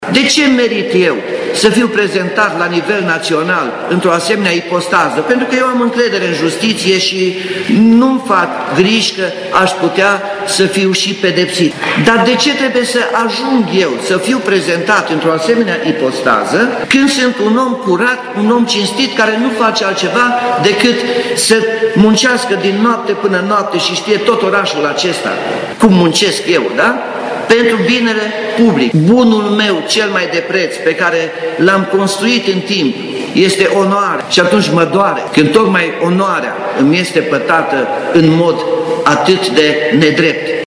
Primarul Timișoarei, despre dosarul DNA în care este suspect: Mă doare când onoarea, bunul meu cel mai de preț, îmi este pătată în mod atât de nedrept